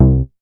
MoogDamm A.WAV